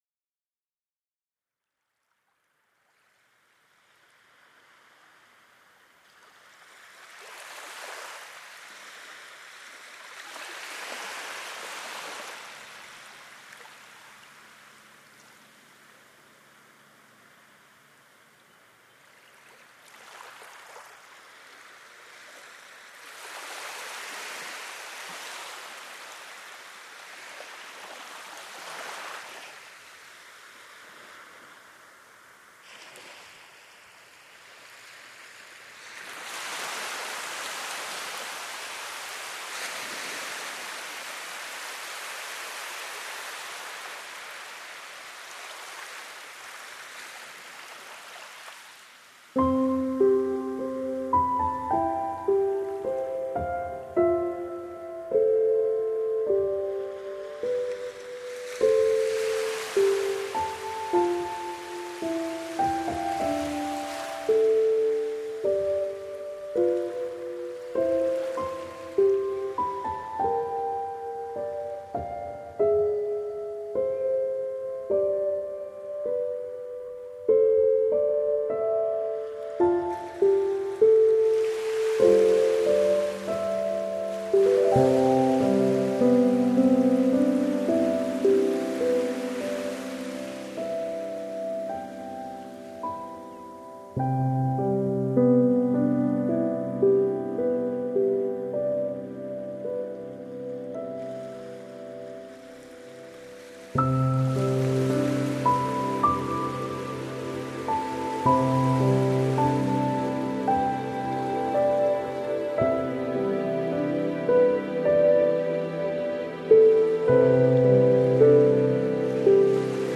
1-4，海浪声；5-6，水声。